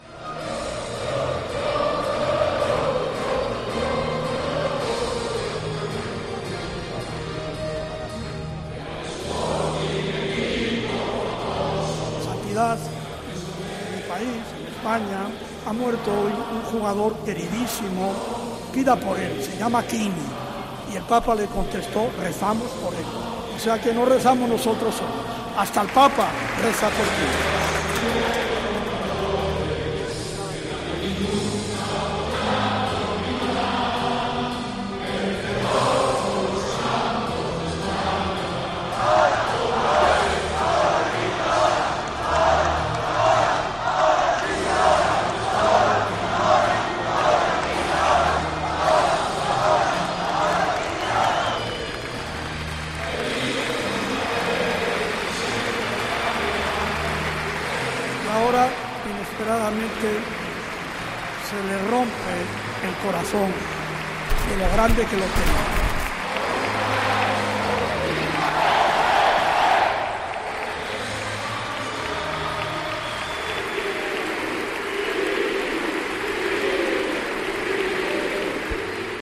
Los sonidos del funeral de Quini en El Molinón-Enrique Castro Quini